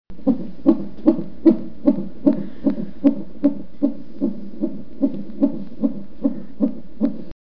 Fetal heart monitor